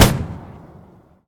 mg-shot-5.ogg